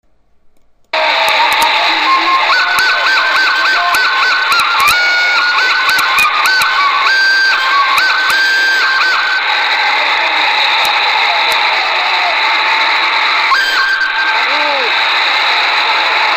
écoutez la joie de la machine.